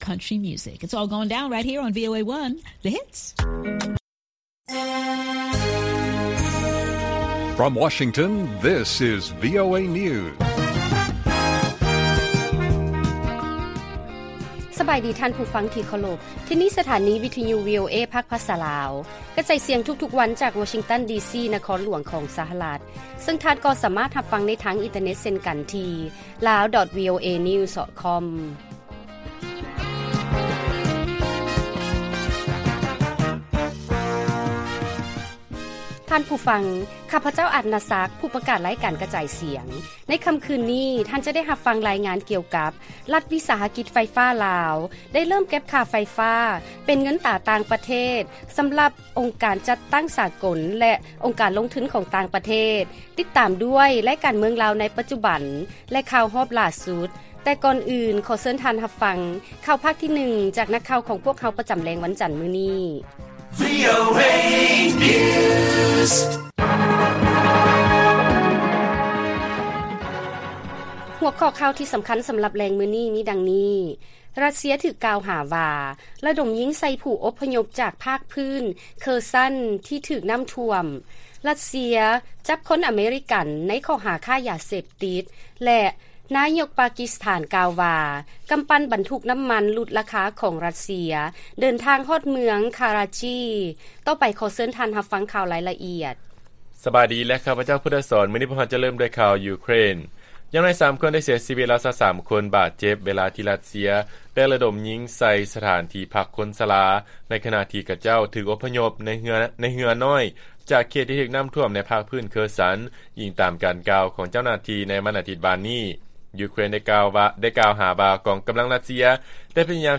ລາຍການກະຈາຍສຽງຂອງວີໂອເອ ລາວ: ຣັດເຊຍ ຖືກກ່າວຫາວ່າ ລະດົມຍິງໃສ່ຜູ້ອົບພະຍົບອອກຈາກພາກພື້ນ ເຄີສັນ ທີ່ຖືກນ້ຳຖ້ວມ